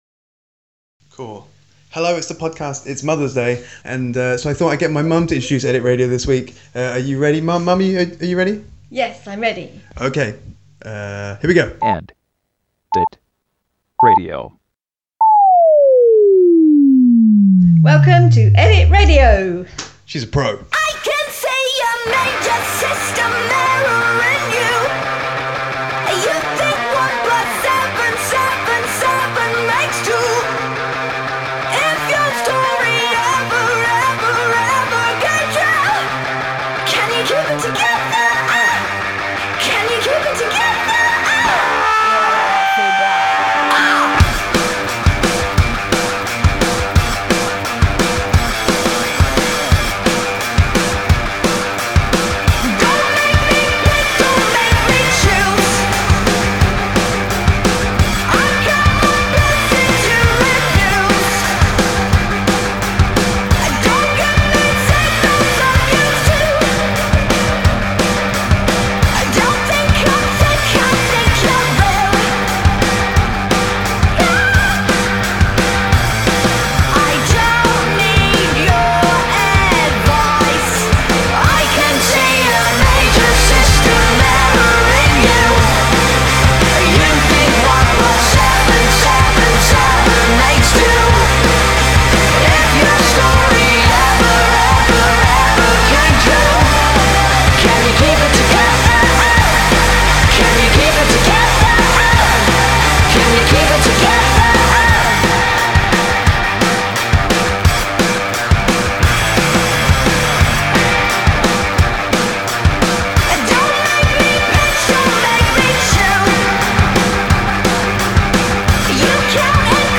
Indie and Alternative